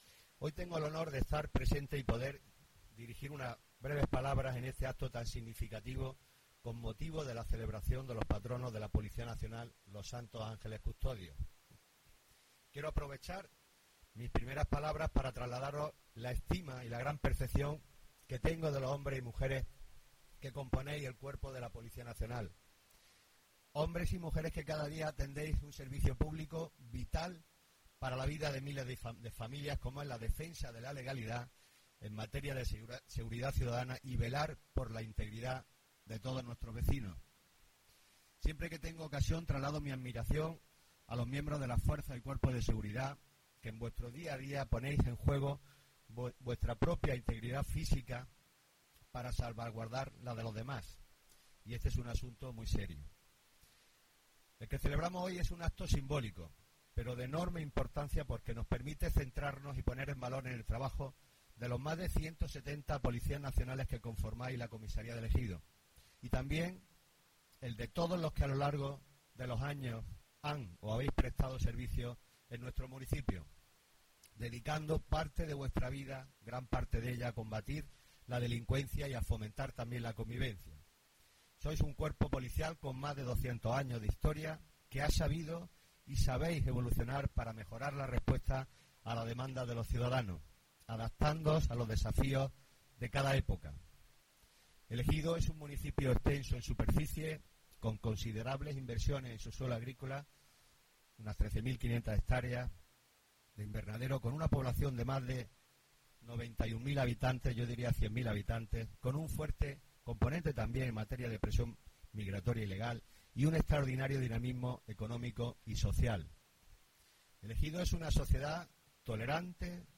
La Plaza Mayor ha acogido el acto institucional de celebración del día del Cuerpo Policial, que ha arrancado con una solemne Misa y ha incluido la entrega de condecoraciones a agentes que han destacado y agradecimientos a personas e instituciones públicas y privadas por su colaboración
ALCALDE-DIA-DE-LA-POLICIA-NACIONAL.mp3